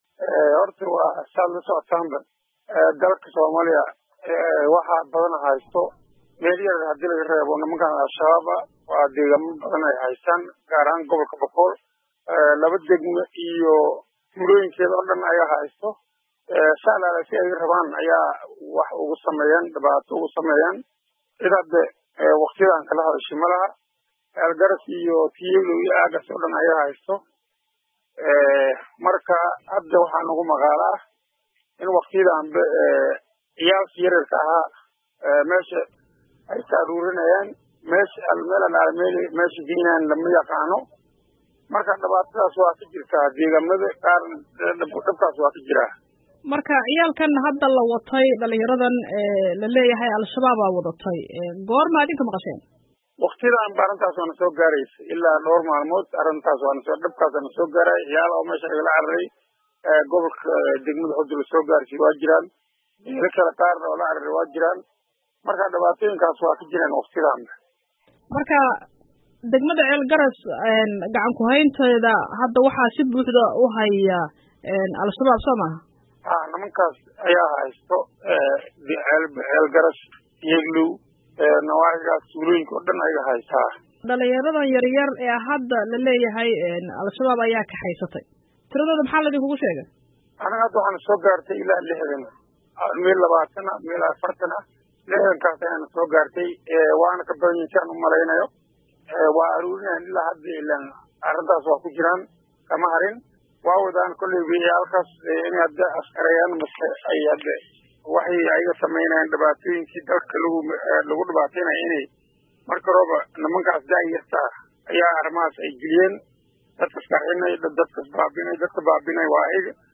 Khadka talefoonka ku wareysatay guddoomiye Maxamed Macallin.